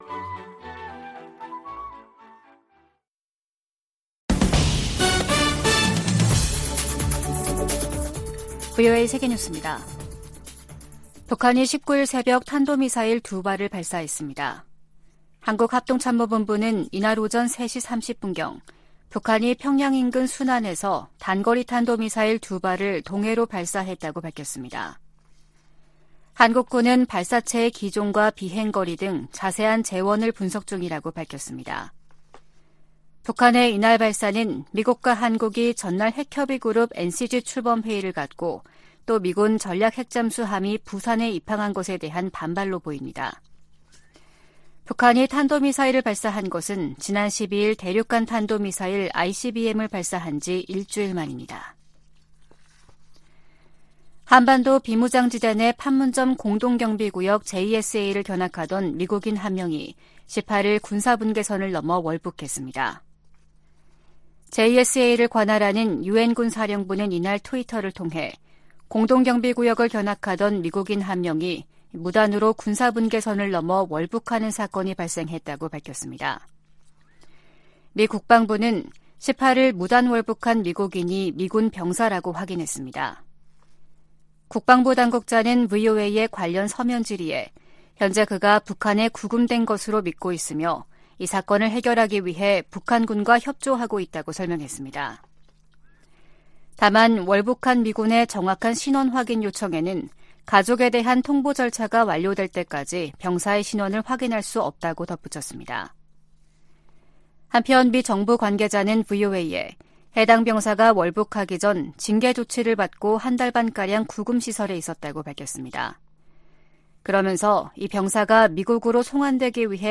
VOA 한국어 아침 뉴스 프로그램 '워싱턴 뉴스 광장' 2023년 7월 19일 방송입니다. 미국과 한국은 18일 서울에서 핵협의그룹(NCG) 첫 회의를 갖고 북한이 핵 공격을 할 경우 북한 정권은 종말을 맞을 것이라며, 확장억제 강화의지를 재확인했습니다. 미 국무부는 북한의 도발에 대한 유엔 안보리의 단합된 대응을 촉구했습니다. 아세안지역안보포럼(ARF)이 의장성명을 내고, 급증하는 북한의 탄도미사일 발사가 역내 평화를 위협한다고 비판했습니다.